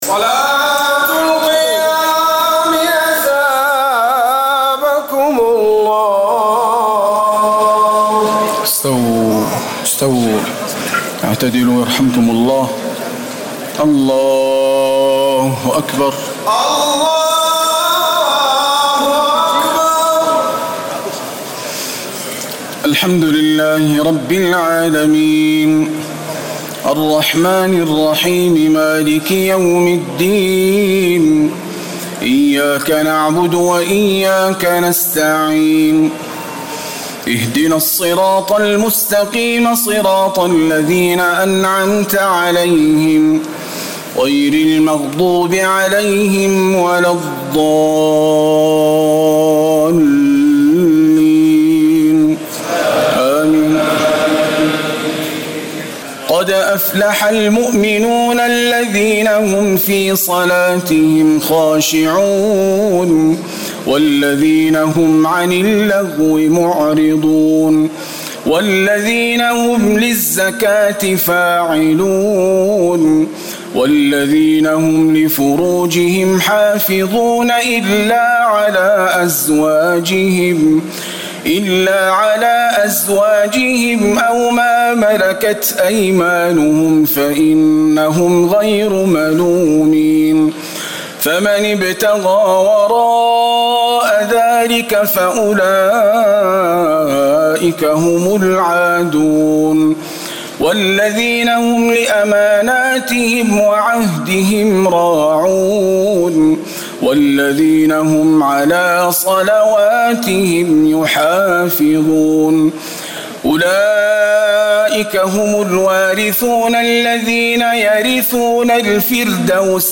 تراويح الليلة السابعة عشر رمضان 1439هـ سورتي المؤمنون و النور (1-20) Taraweeh 17 st night Ramadan 1439H from Surah Al-Muminoon and An-Noor > تراويح الحرم النبوي عام 1439 🕌 > التراويح - تلاوات الحرمين